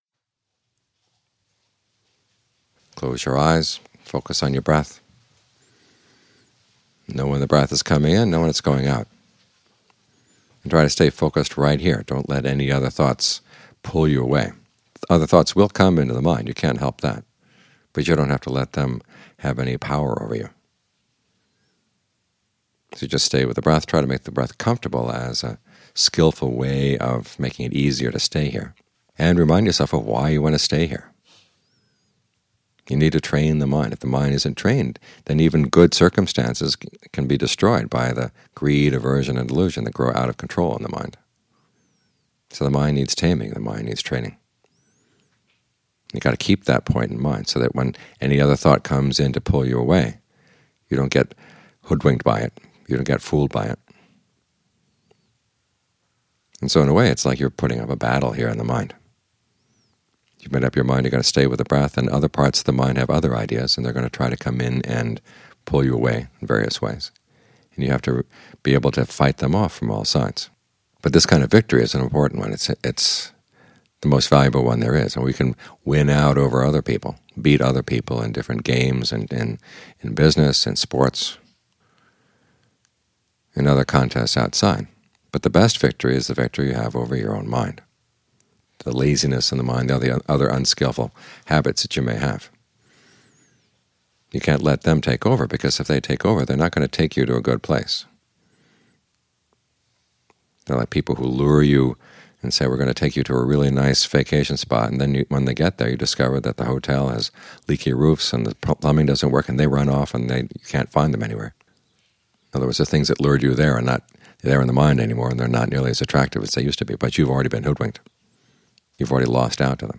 Morning Talks (2011)